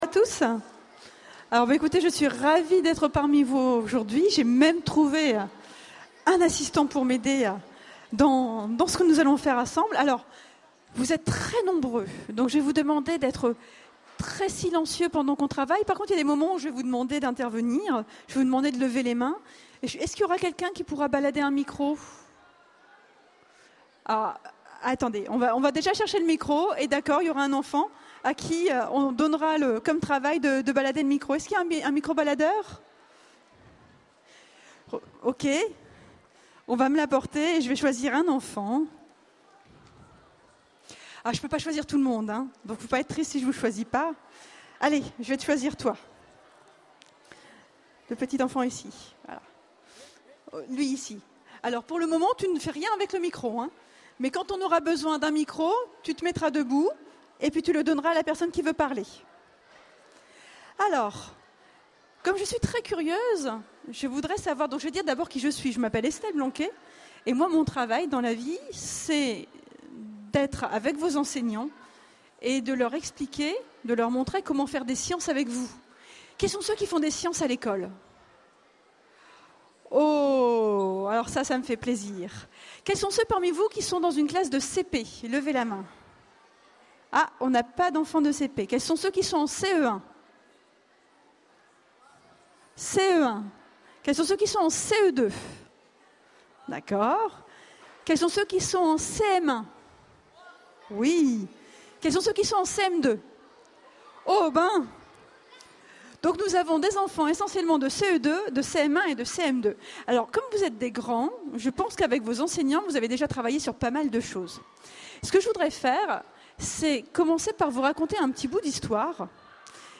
Conférence
Mots-clés Rencontre avec un auteur Conférence Partager cet article